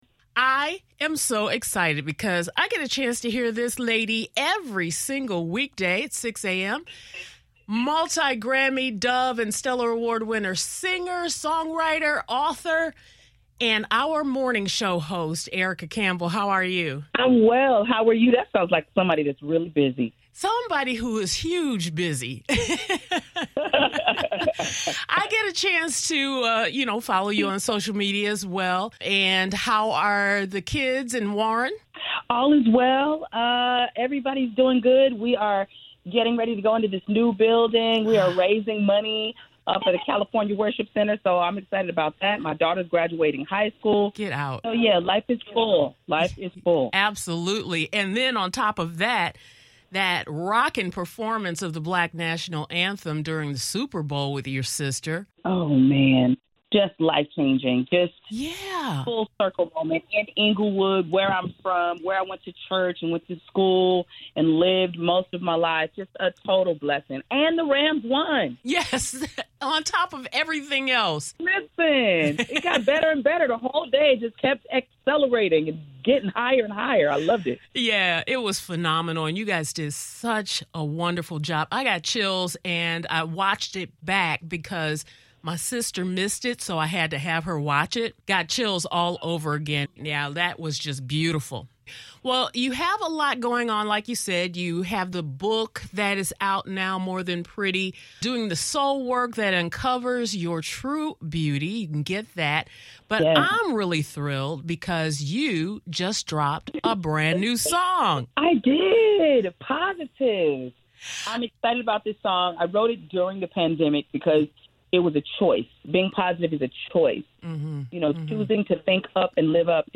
Thinking “Positive” with New Music from Erica Campbell [[Interview]]